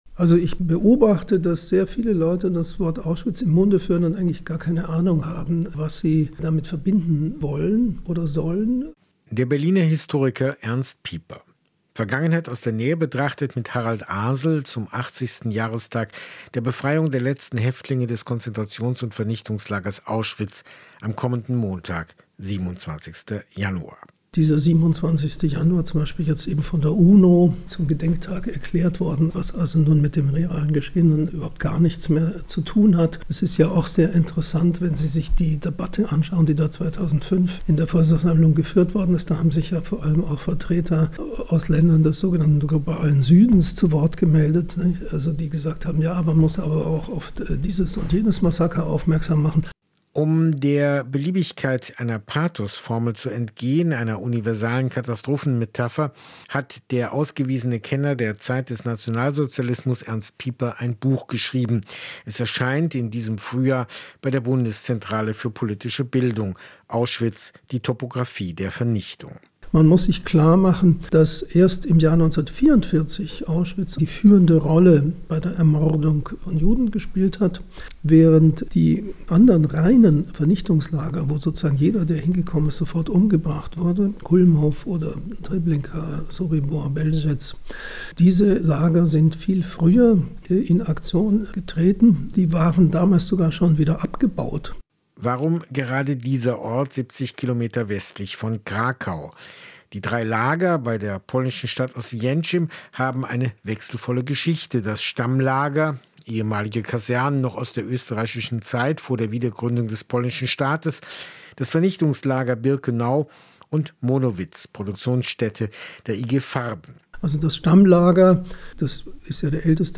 Anhand von Reportagen, Gesprächen und Debatten widmet sich das Magazin dem Spannungsfeld zwischen gestern und heute. Geschichte sucht nach dem Wirken von Strukturen, Institutionen und Weltanschauungen, will Vergangenes sinnlich erfahrbar machen und Denkanstöße geben.